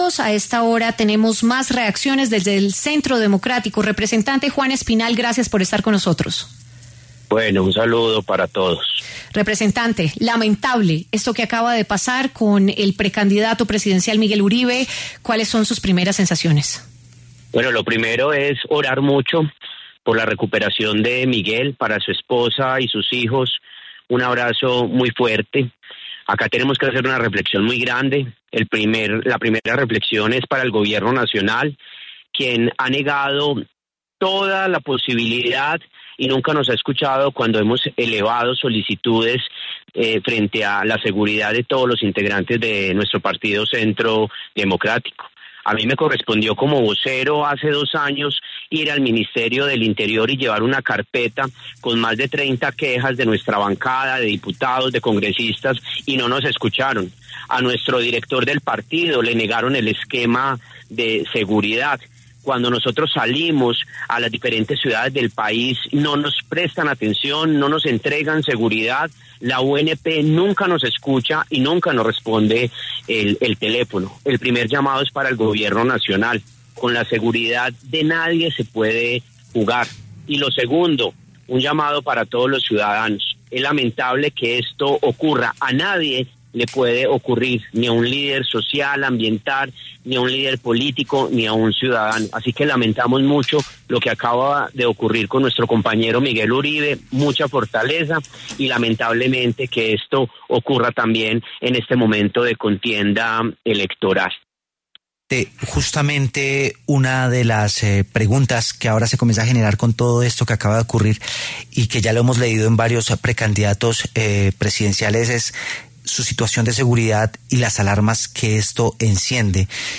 Juan Espinal, representante a la Cámara del Centro Democrático, se refirió en W Radio al atentado contra el precandidato presidencial Miguel Uribe.